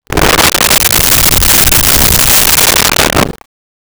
Creature Breath 02
Creature Breath 02.wav